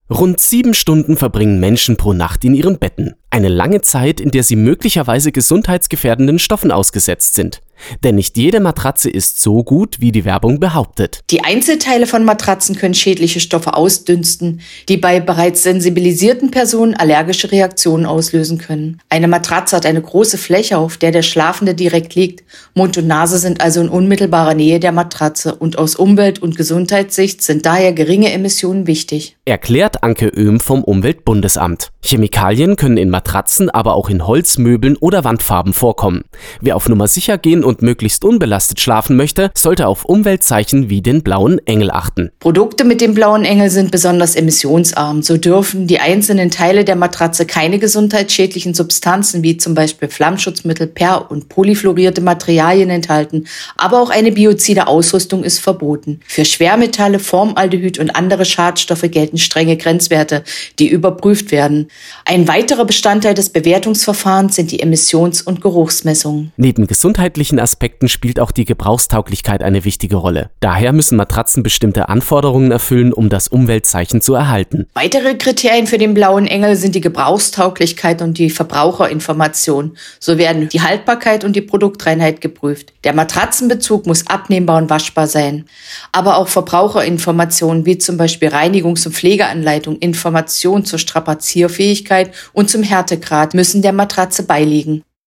Radio contributions